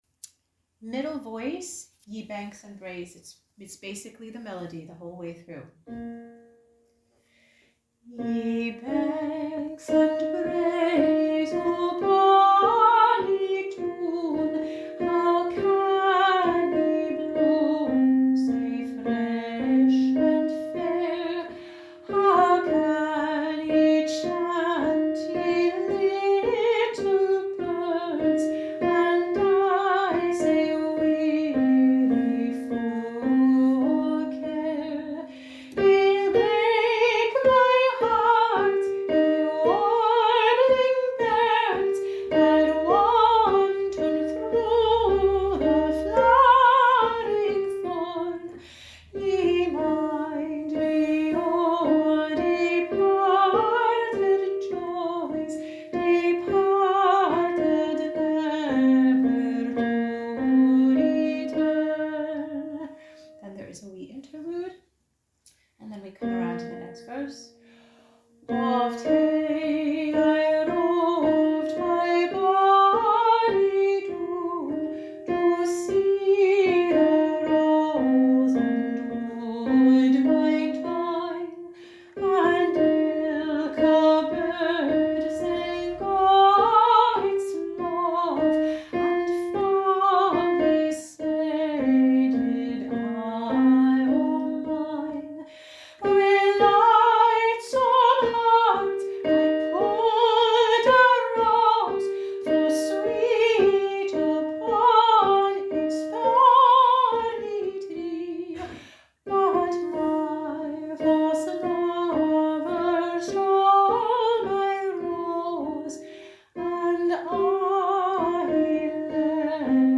ye-banks-and-braes-v2-melody.mp3